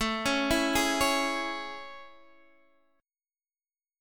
AM7 Chord
Listen to AM7 strummed